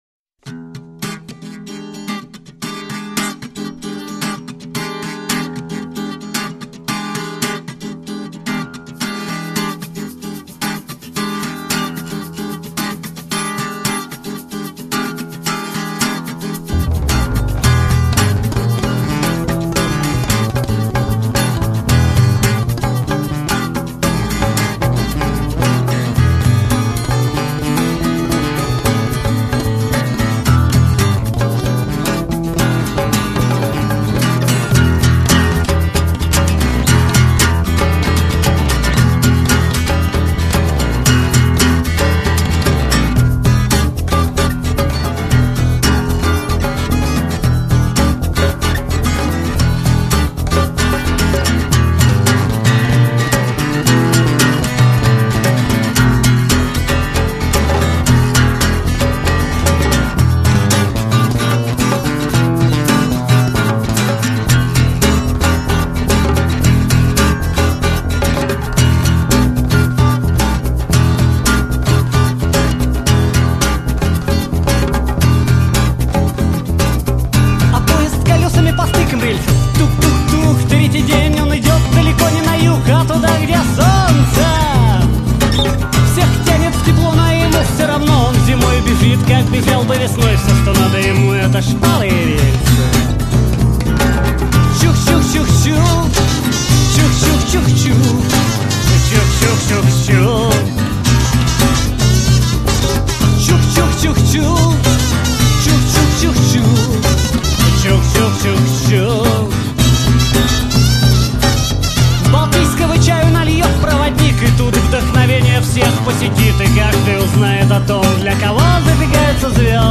Концерт в студии
вокал, казу
гитара
конго, дарбука, тамбурин, шейкер
бас